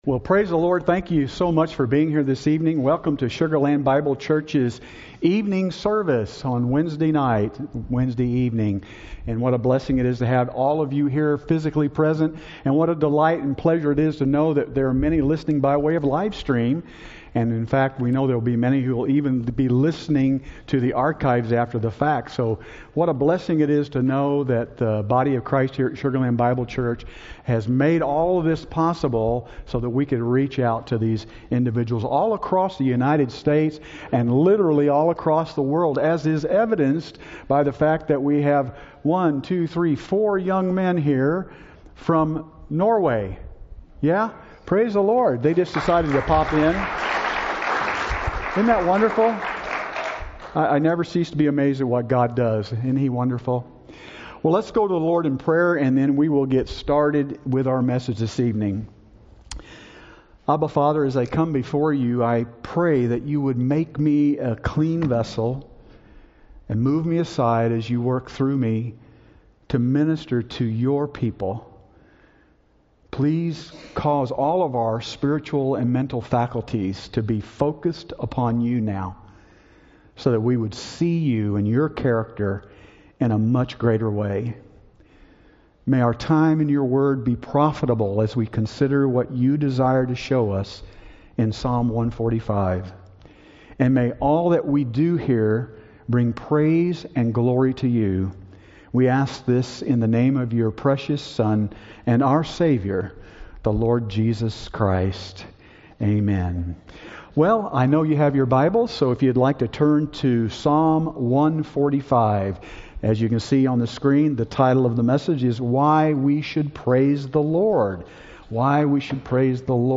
Topical Sermons